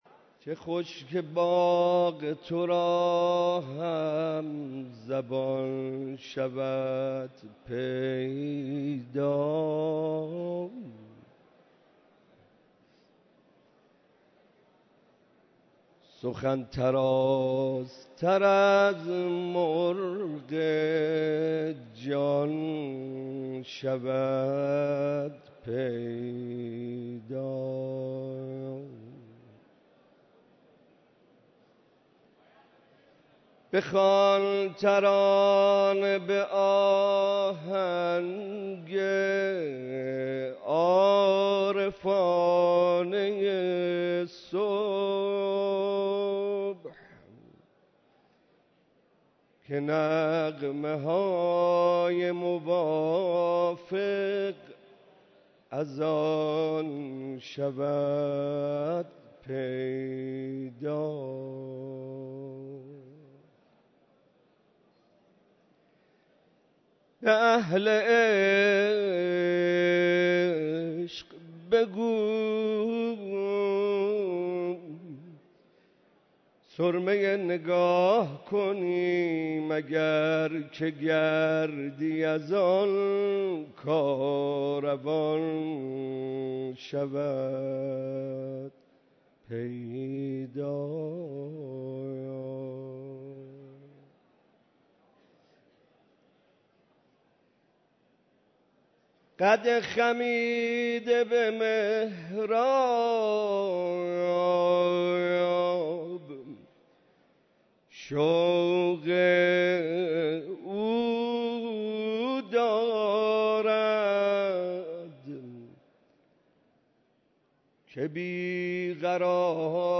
دیدار هزاران نفر از اقشار مختلف مردم در روز عید غدیر خم
مداحی